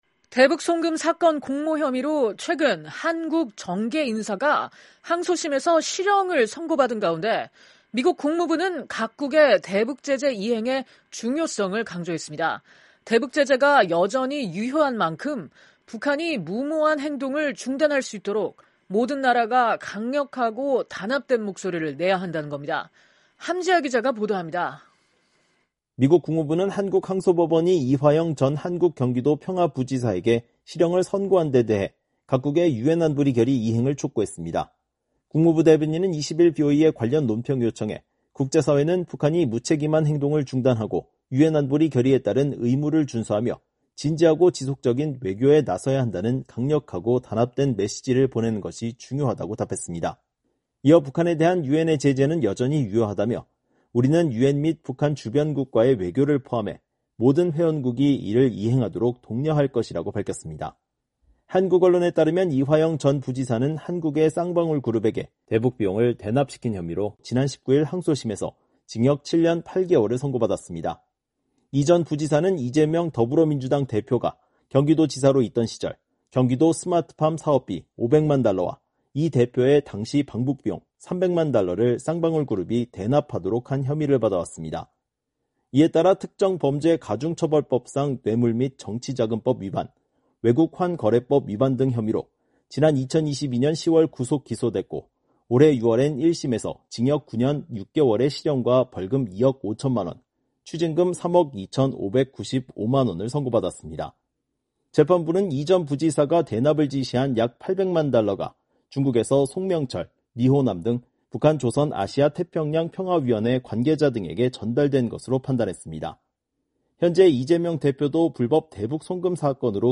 속보